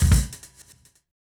kick-hat01.wav